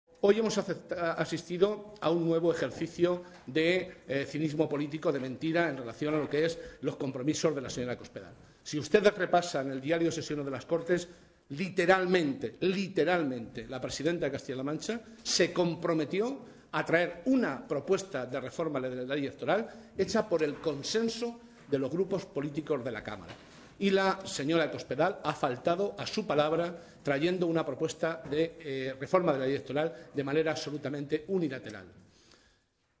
José Molina, diputado regional del PSOE de Castilla-La Mancha
Molina, que compareció ante los medios en la sala de prensa de la Cámara autonómica, indicó que si se lee el diario de sesiones de las Cortes, en el mismo, Cospedal literalmente se comprometió a traer una propuesta de reforma de la ley electoral hecha con el consenso de los grupos políticos de la cámara autonómica, “cosa que no ha hecho hoy, faltando a su palabra, al traer una propuesta unilateral”.
Cortes de audio de la rueda de prensa